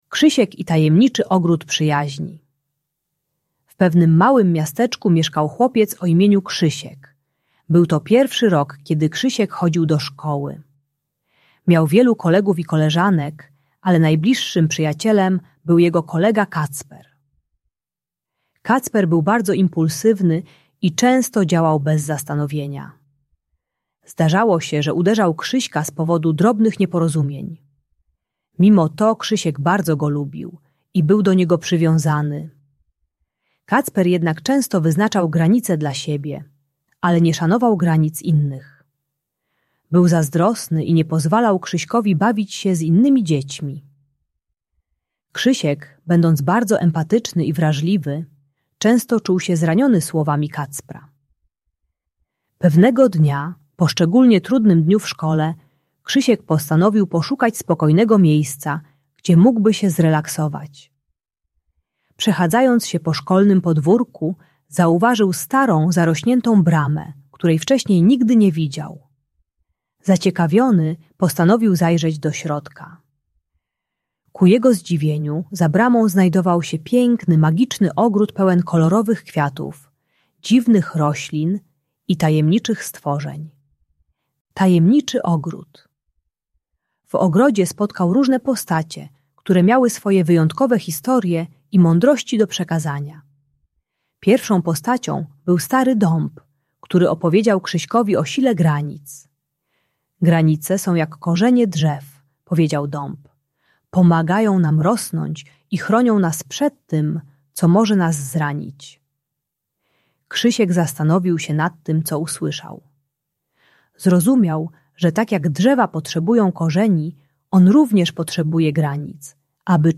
Uczy jak stawiać granice i mówić "nie" gdy kolega bije lub kontroluje. Audiobajka o przyjaźni i szacunku dla uczniów pierwszych klas.